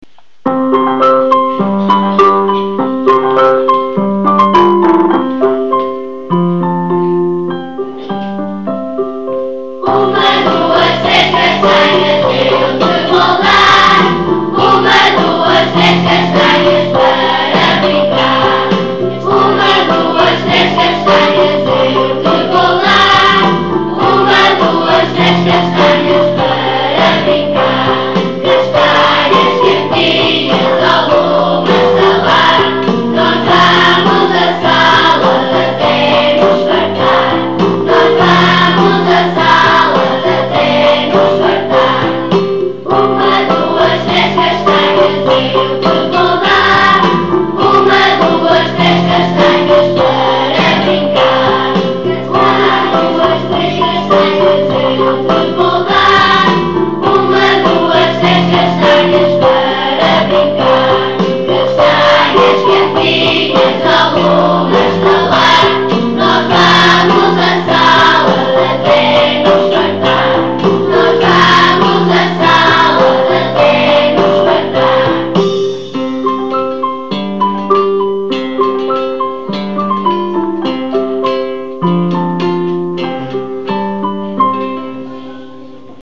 Duas musicas que os alunos cantaram: “Castanhas” e “Uma, duas, três castanhas” (uma vez que os alunos também estão a dar os números até 10…)